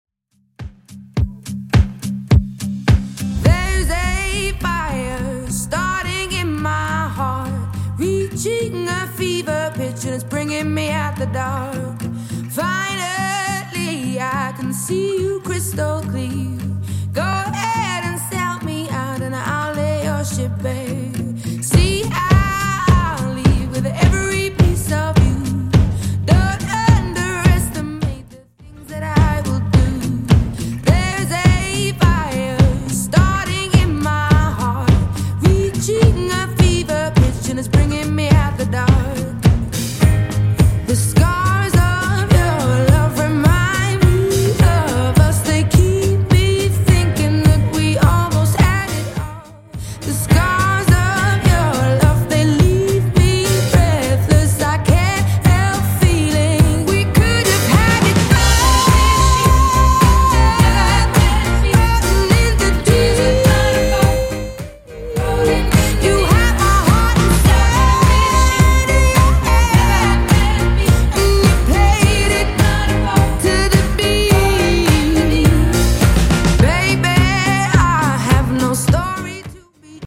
Genre: 70's